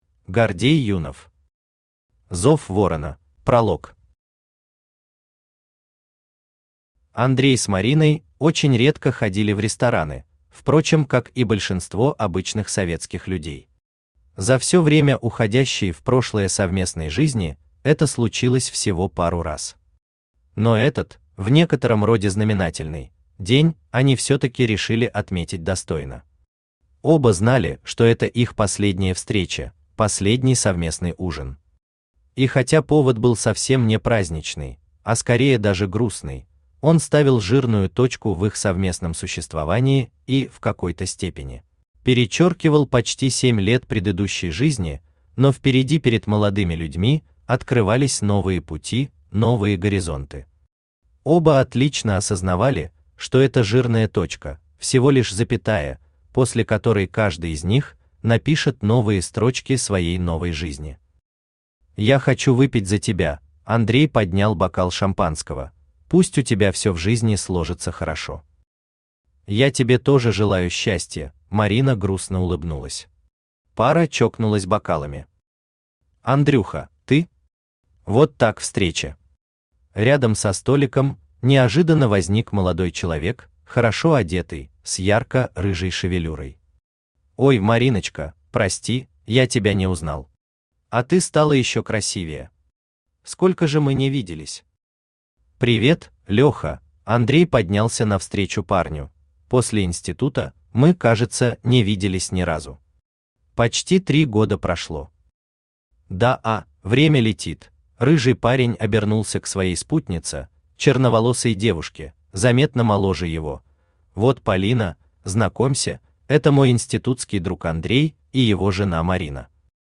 Aудиокнига Зов ворона Автор Гордей Юнов Читает аудиокнигу Авточтец ЛитРес.